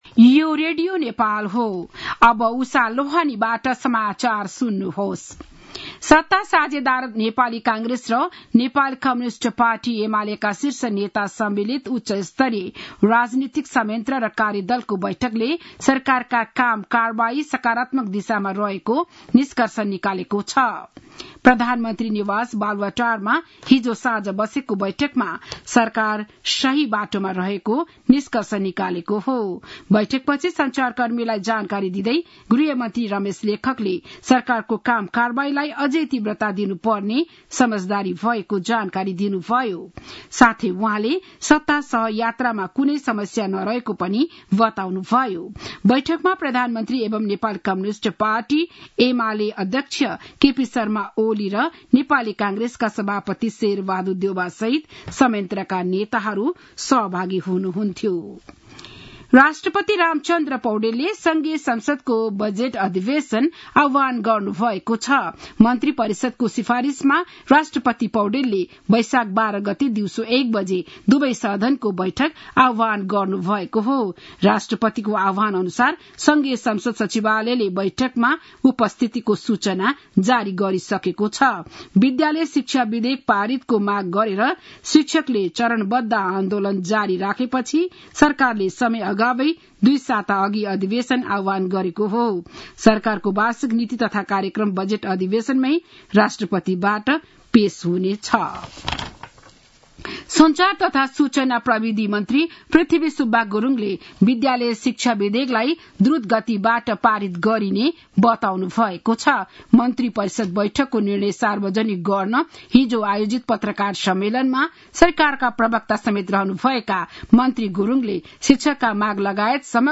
बिहान ११ बजेको नेपाली समाचार : ४ वैशाख , २०८२
11-am-news-1-7.mp3